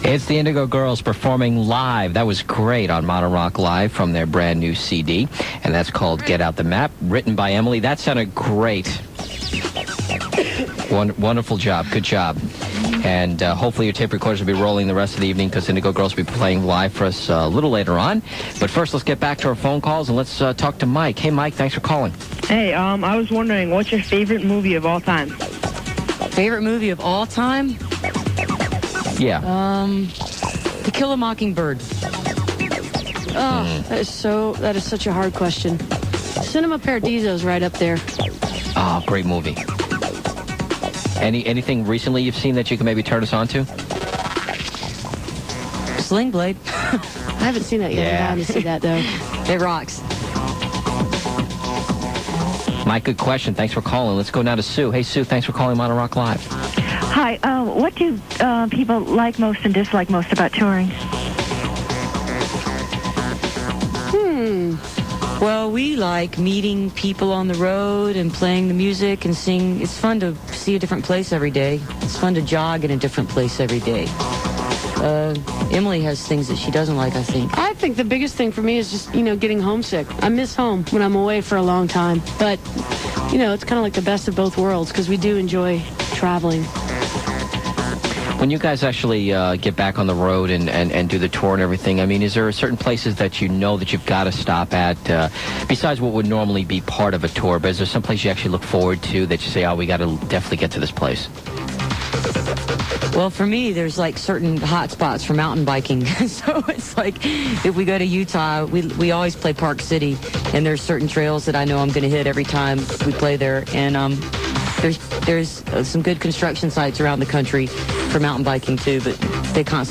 06. interview (2:46)